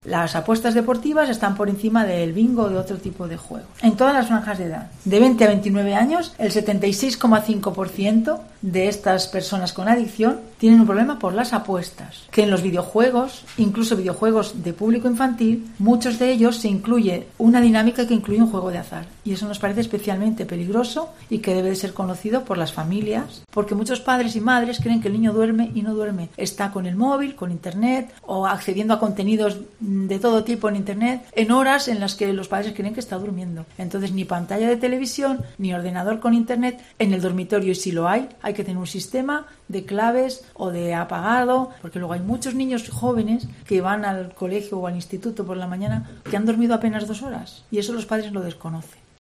La directora general de Salud Pública, Nuria Gayán, detalla algunos de los datos del informe elaborado con motivo del Día sin Juego en Aragón